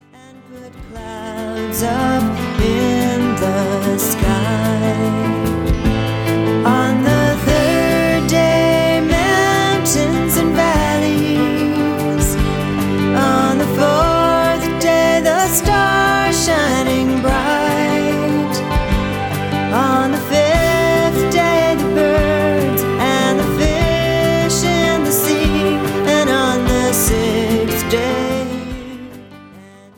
spirited, sincere songs
rich folk voice